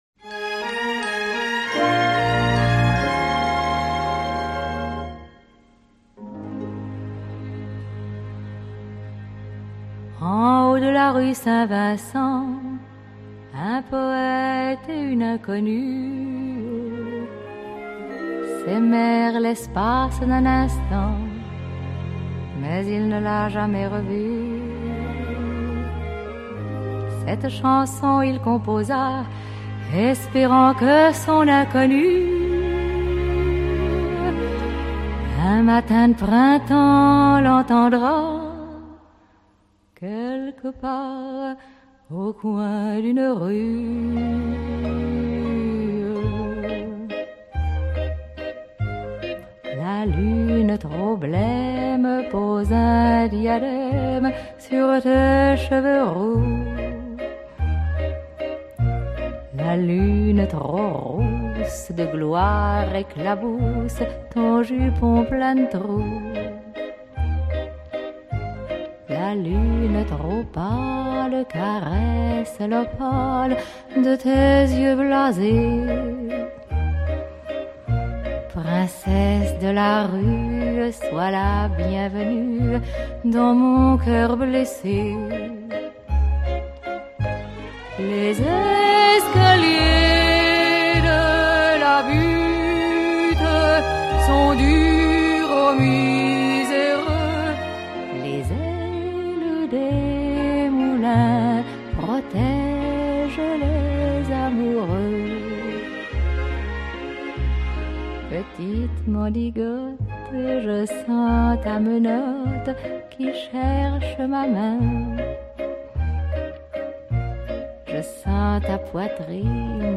Cours radiophonique histoire géographie de terminale
(enregistré chez lui)